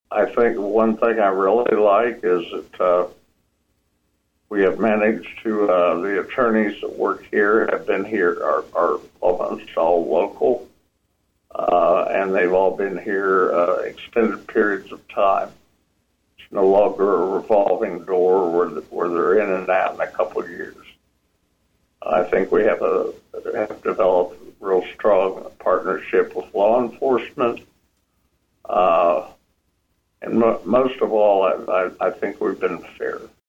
He used the end of KVOE’s Talk of Emporia on Friday to officially announce he’s not seeking re-election.